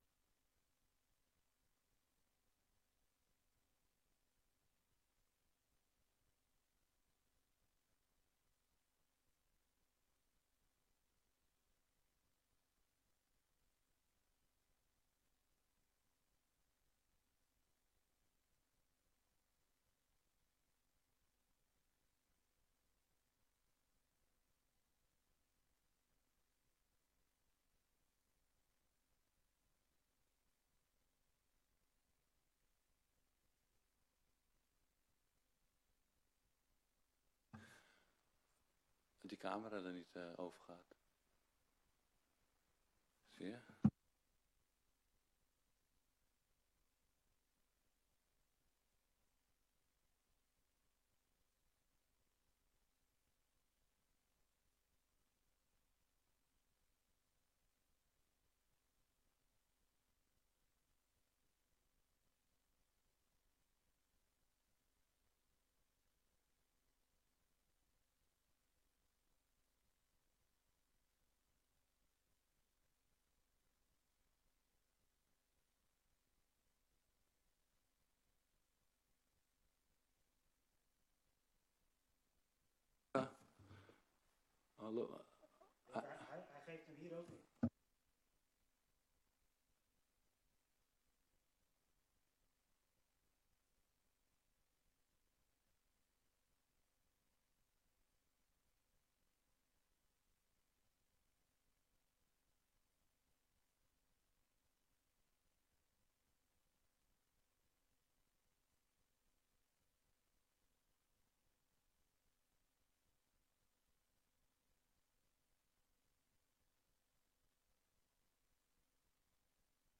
Algemene Raadscommissie Sessie B in De Beeck, Molenweidtje 2, 1862 BC Bergen.